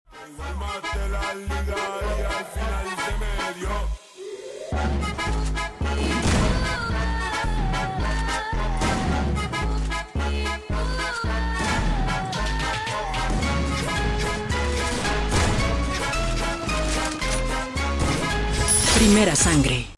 New updated sensitivity + headshot sound effects free download